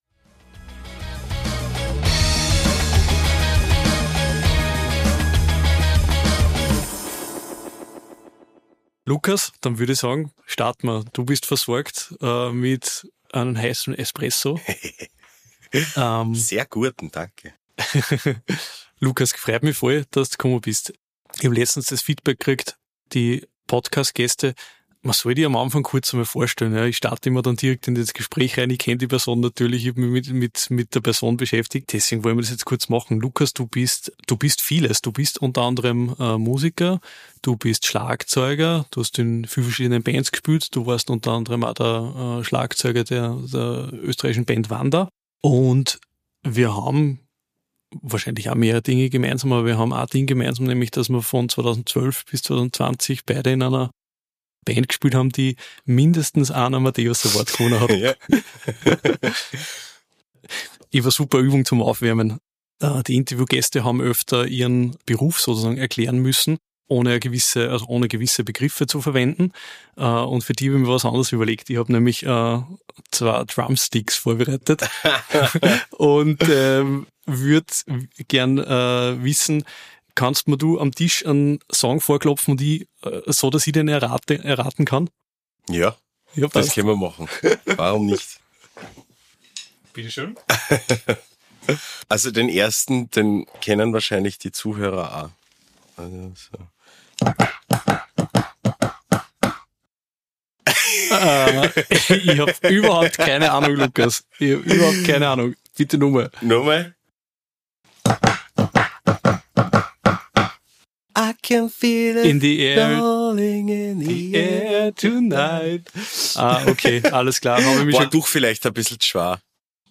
Ein Gespräch über Veränderung und den Mut, den eigenen Weg weiterzugehen – auch wenn er in eine neue Richtung führt.